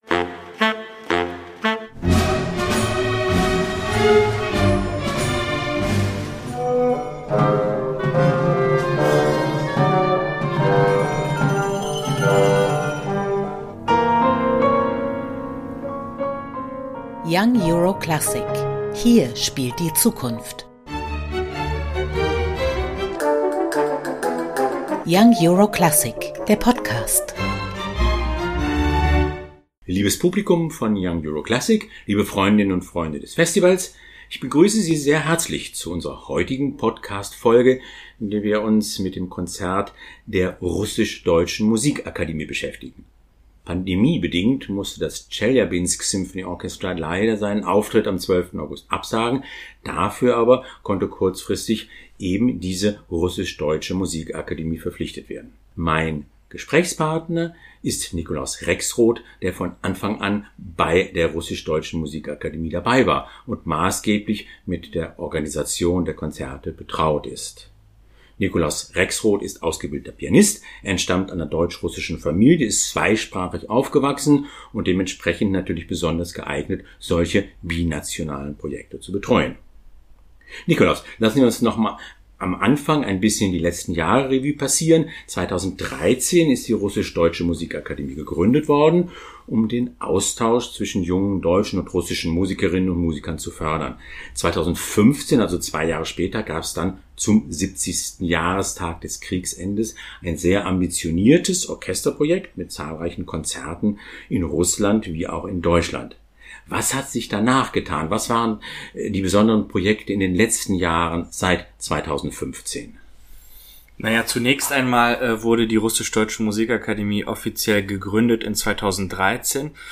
Ein Gespräch über das hochambitionierte Projekt kulturellen Austauschs, den Reichtum des deutsch-russischen Repertoires – und die ausgezeichneten jungen Musiker:innen, die am 12. August statt des Chelyabinsk Symphony Orchestras die Bühne von Young Euro Classic betreten werden.